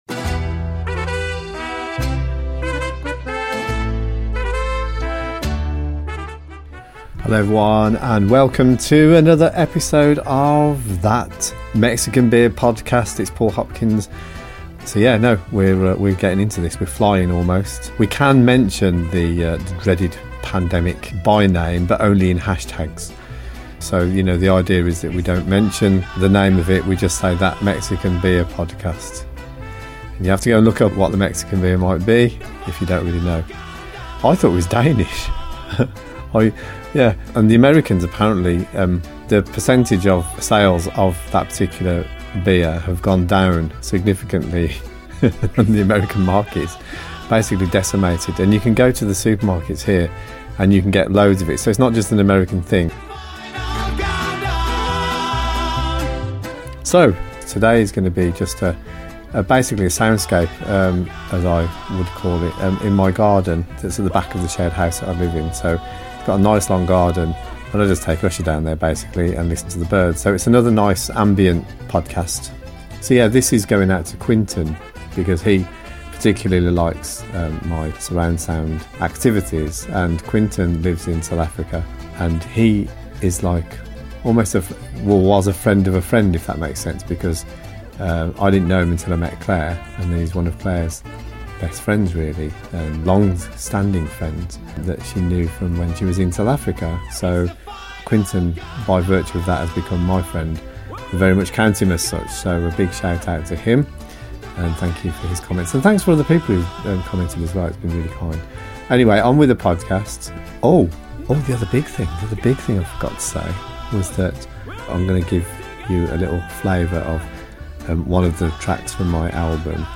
The music of the birds is interspersed with part of a track from my album, you know, that one I've been going on about releasing for ages. Wearing headphones is recommended and as always, Enjoy...